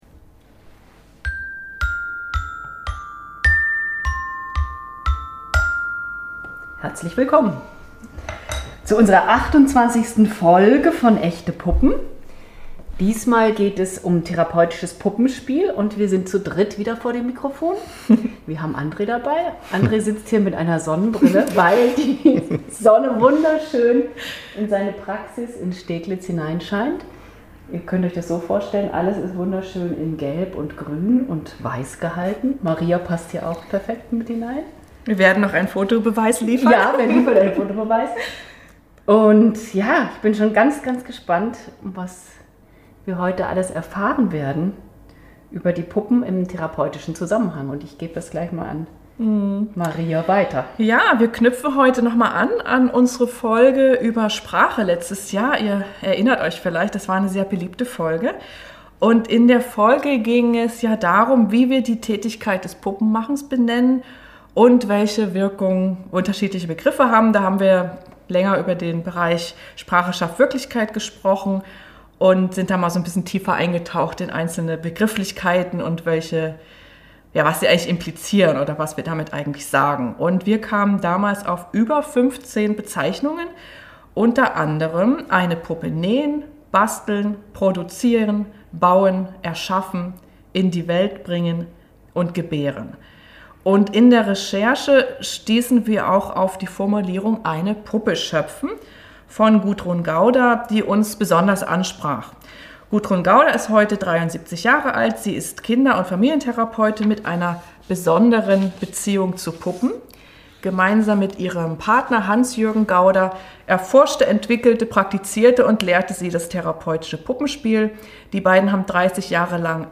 #28 Therapeutisches Puppenspiel: Interview